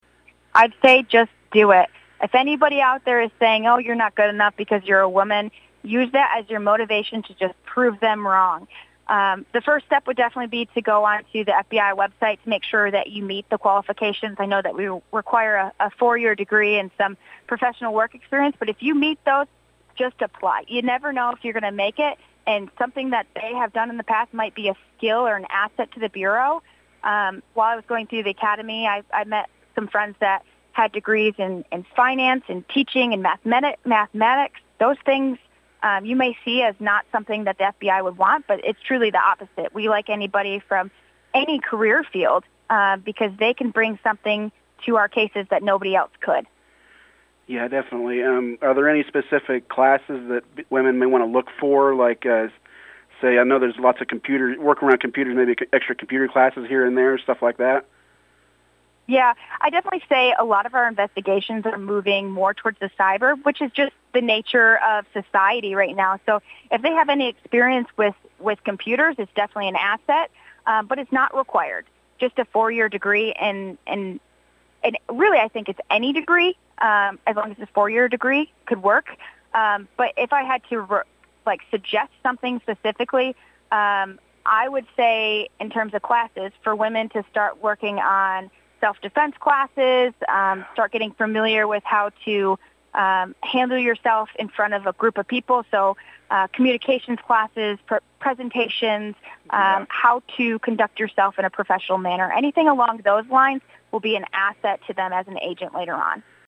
the Cromwell News Team held a phone interview